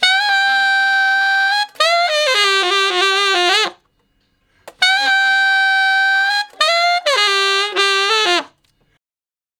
066 Ten Sax Straight (D) 27.wav